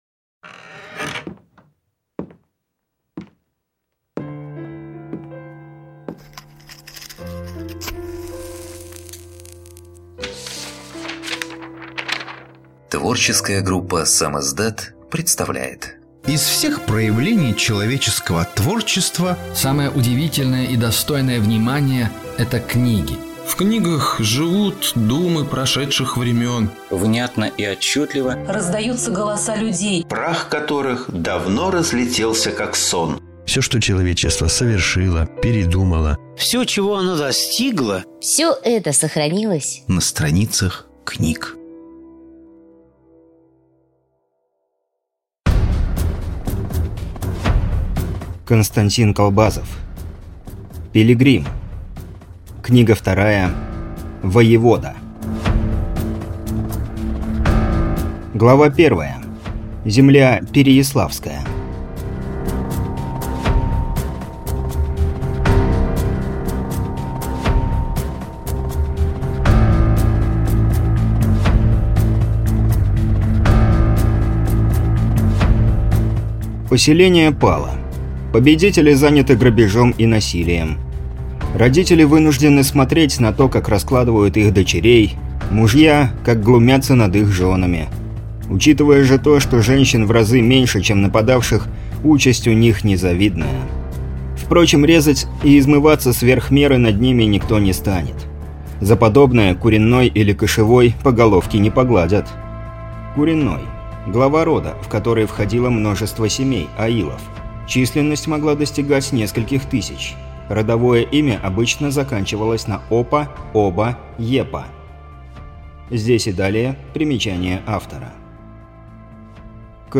Прослушать фрагмент аудиокниги Пилигрим.